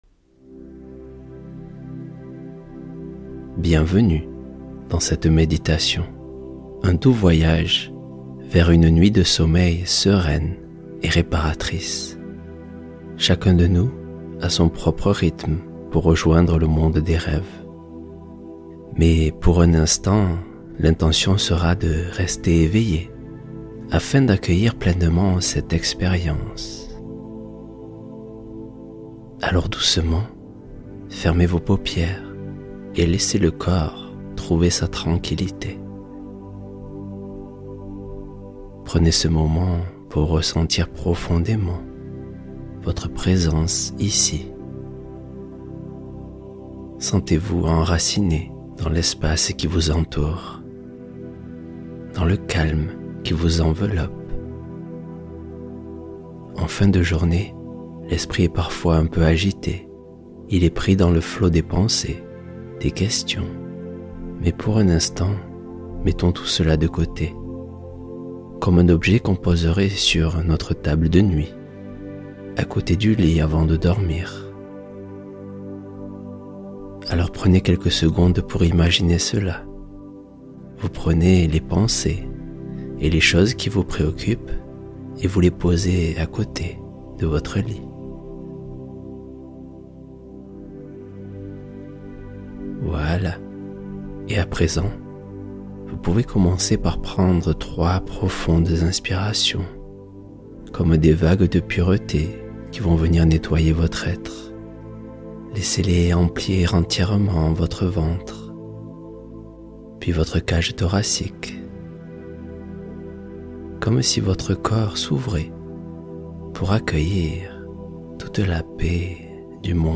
Matin de gratitude — Méditation pour ouvrir la journée avec présence